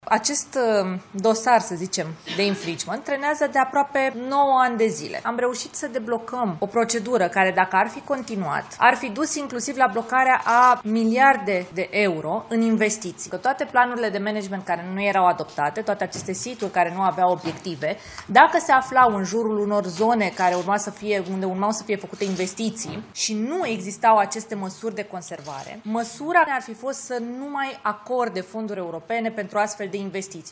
Ministra Mediului, Diana Buzoianu, a explicat la Palatul Victoria că guvernanții au reușit să deblocheze o procedură în acest caz, care – fără implicare – ar fi dus la blocarea a miliarde de euro în investiții.
Ministra Mediului, Diana Buzoianu: „Am reușit să deblocăm o procedură care, dacă ar fi continuat, ar fi dus inclusiv la blocarea a miliarde de euro în investiții”